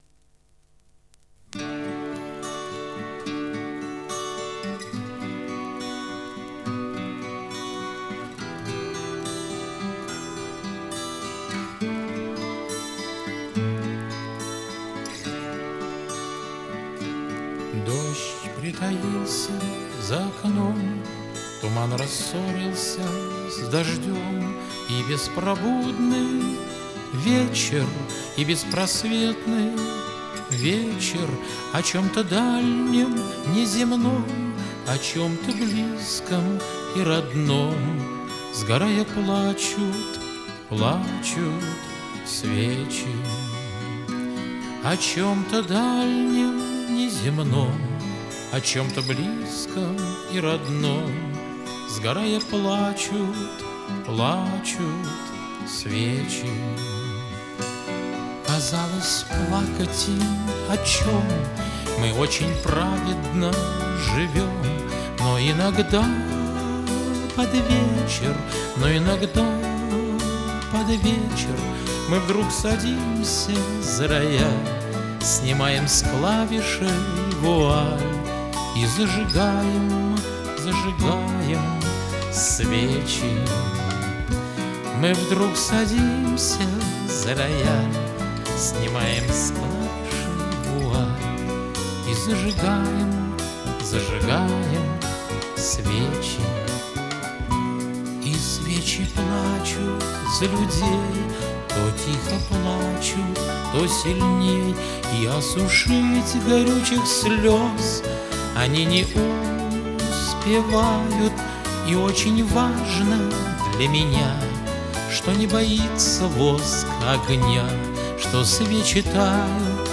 в авторском исполнении